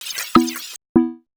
UI_SFX_Pack_61_44.wav